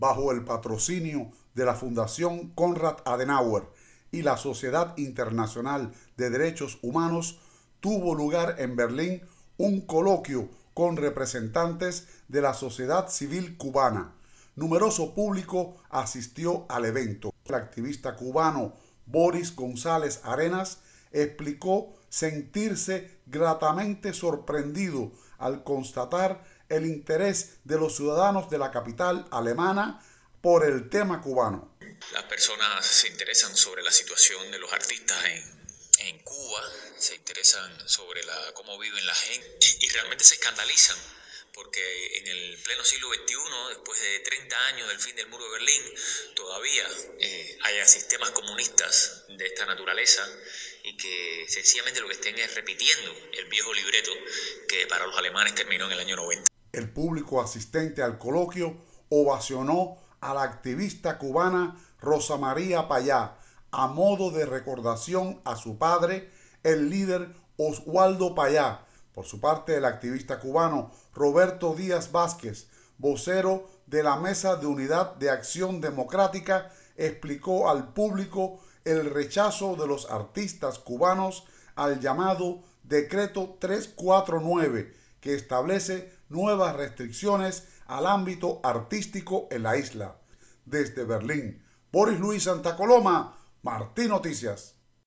El evento "Voces de Cuba" celebrado en la Fundación Konrad Adenauer, con sede en Berlin, reunió a representantes de la sociedad civil y activistas opositores en la isla.